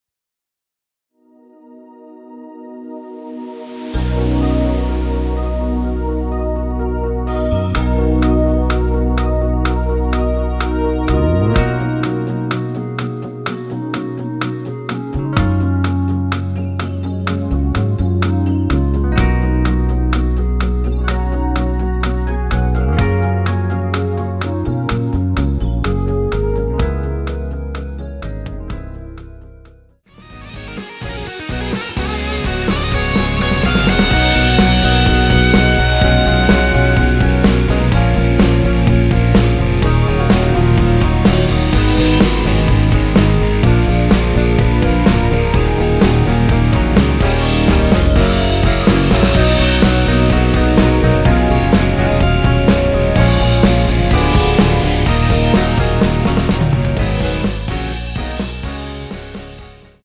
원키에서(-3)내린 멜로디 포함된 MR입니다.(미리듣기 확인)
Gb
앞부분30초, 뒷부분30초씩 편집해서 올려 드리고 있습니다.
중간에 음이 끈어지고 다시 나오는 이유는